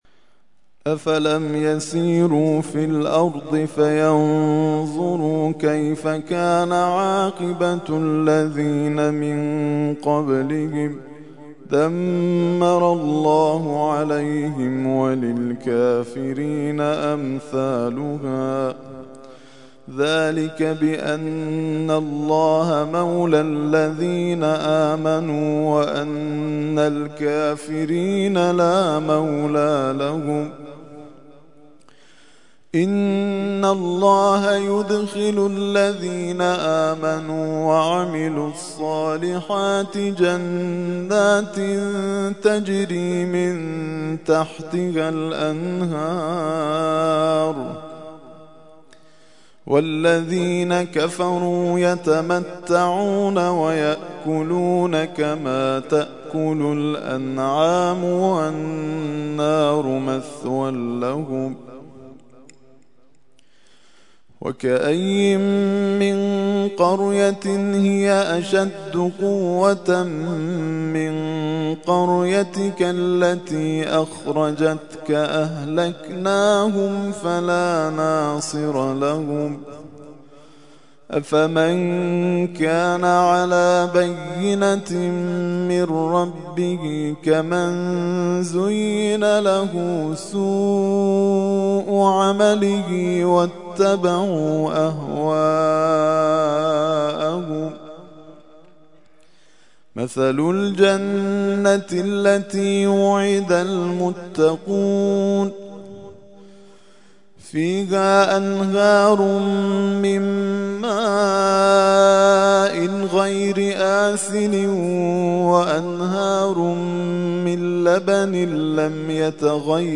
ترتیل خوانی جزء ۲۶ قرآن کریم در سال ۱۳۹۱